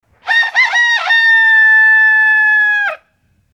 Rooster Crowing Type 01 Efecto de Sonido Descargar
Rooster Crowing Type 01 Botón de Sonido